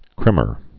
(krĭmər)